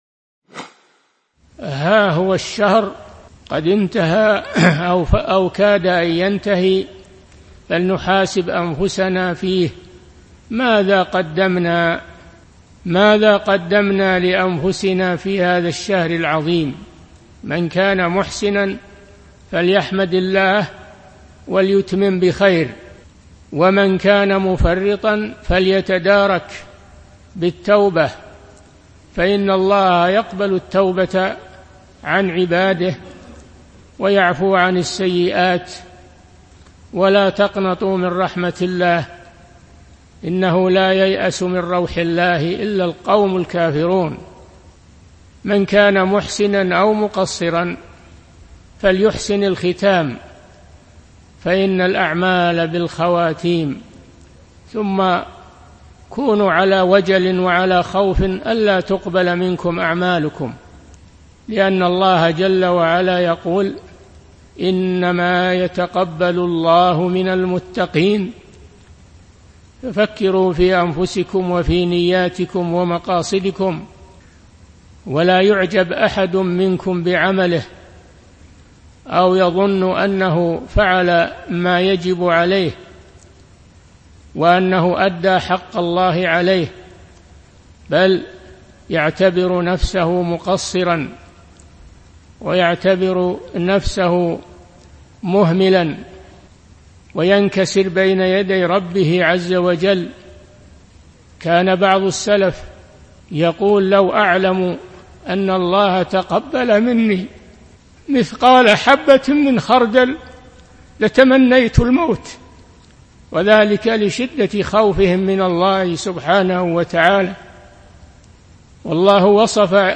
موعظة عظيمة في وداع رمضان - الشيخ د. صالح الفوزان
من مواعظ أهل العلم